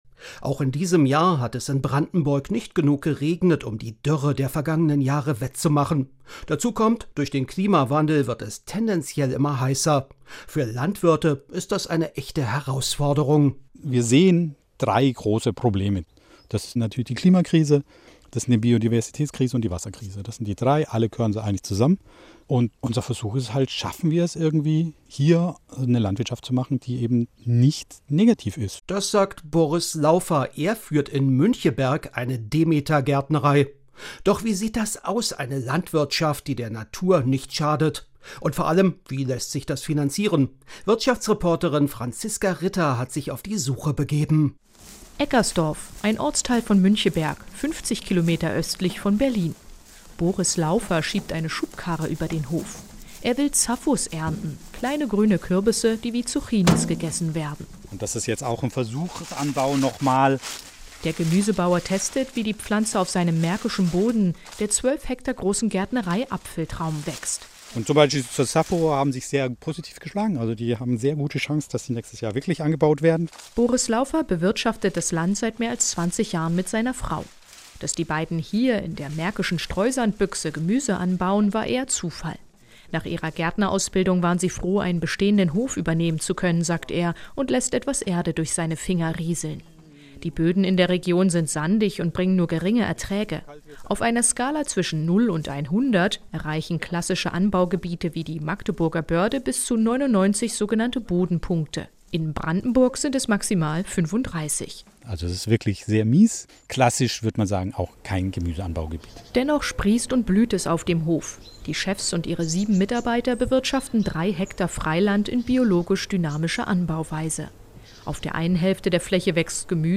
Die Wirtschaftsreportage - Hunger auf Biolebensmittel: Brandenburg will liefern